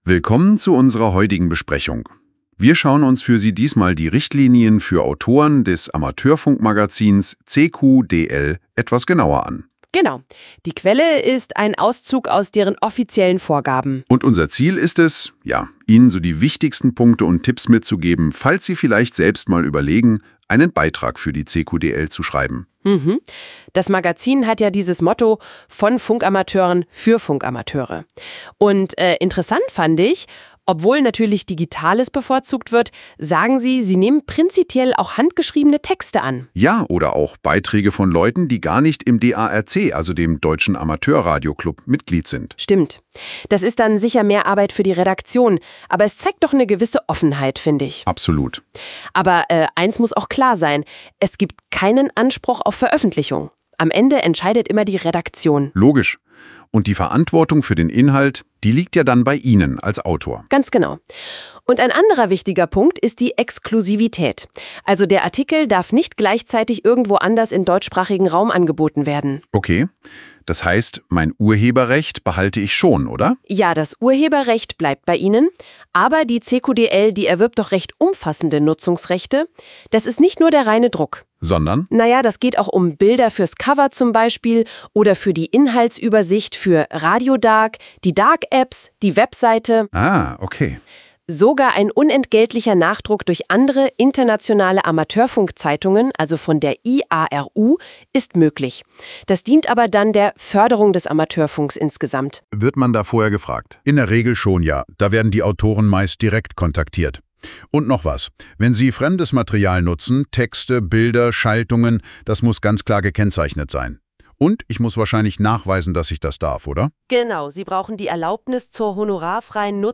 Diese Autorenhinweise als Audio-Podcast im MP3-Format (KI-generiert)
autorenhinweise_cqdl_ki.mp3